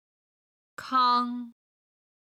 75位　康　(kāng)　康　コウ